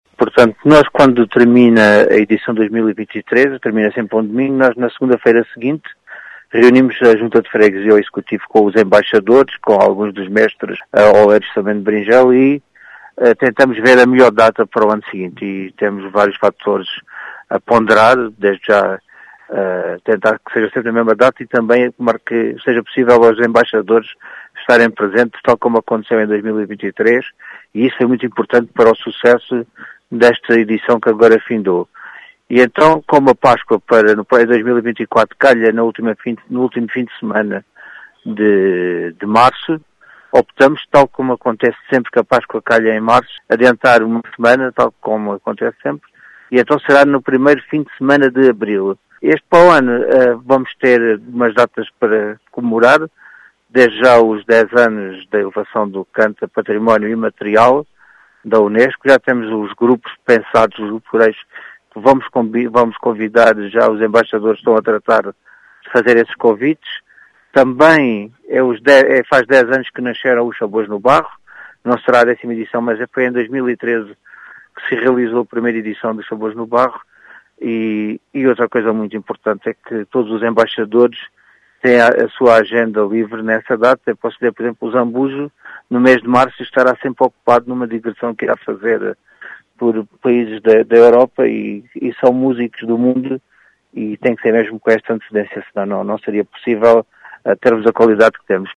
As explicações são de Vítor Besugo, presidente da Junta de Freguesia de Beringel, que lança os preparativos para a edição do próximo ano.